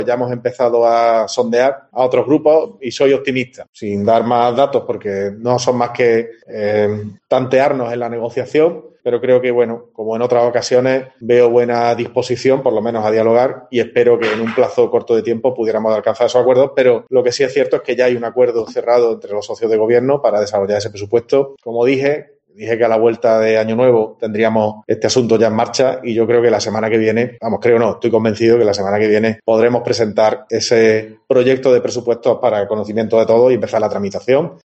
En una rueda de prensa, junto a la secretaria general del PP regional y presidenta del Grupo Popular en el Parlamento de Andalucía, Dolores López, Bellido ha destacado que "ya se ha hecho el trabajo interno del gobierno", de modo que "los socios PP y Cs ya hemos alcanzado un acuerdo en cuanto a las cifras del presupuesto", ha elogiado.